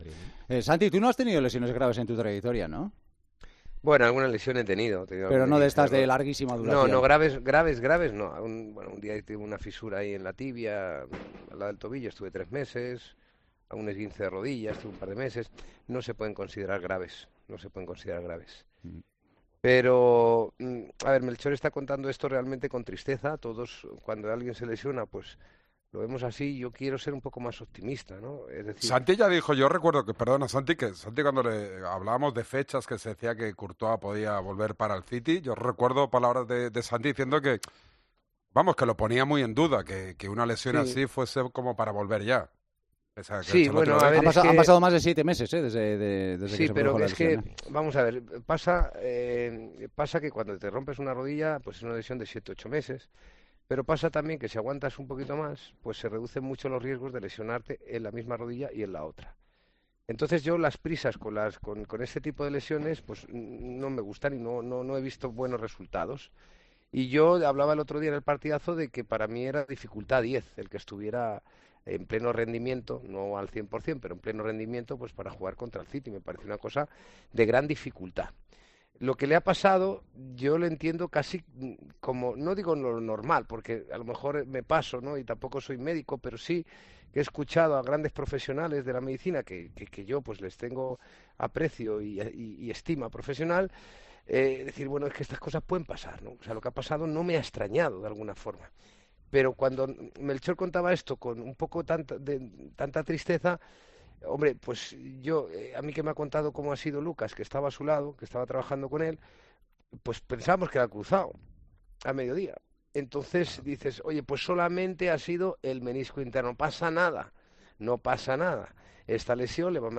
El exportero analiza en El Partidazo de COPE el problema físico que vuelve a alejar al guardameta del Real Madrid de los terrenos de juego